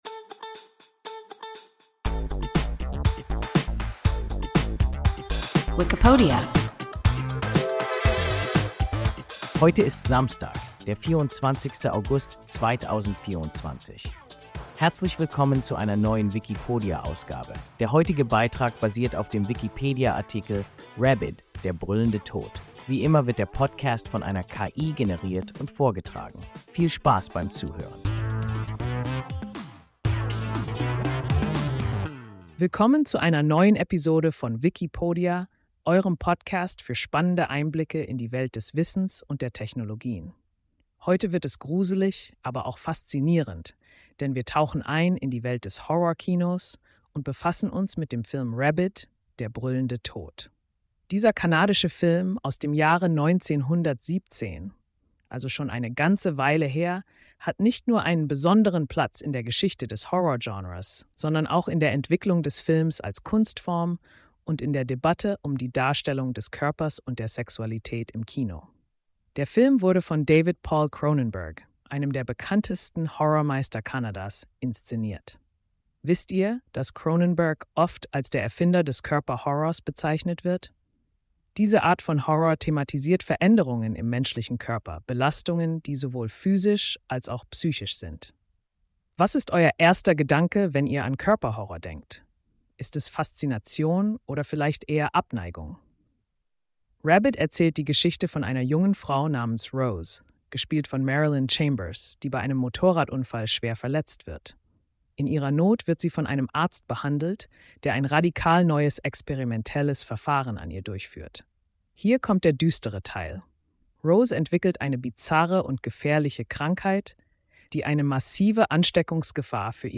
Rabid – Der brüllende Tod – WIKIPODIA – ein KI Podcast